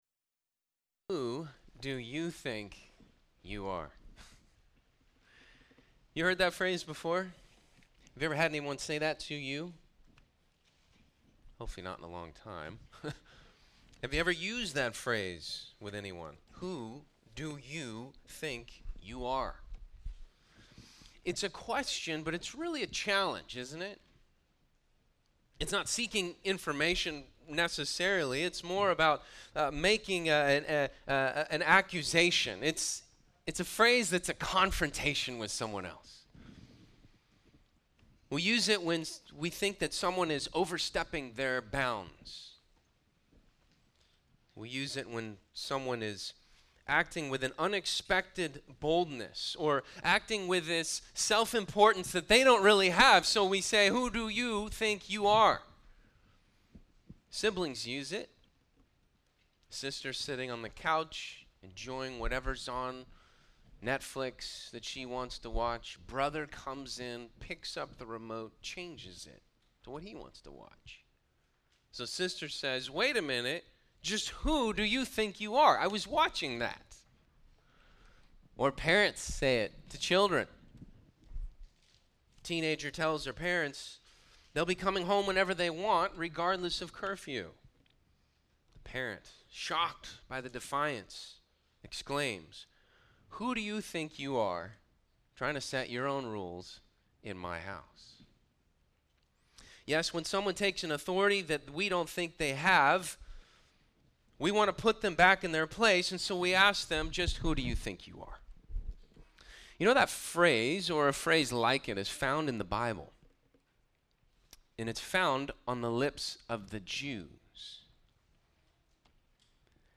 Sermons
From Series: "All Sermons"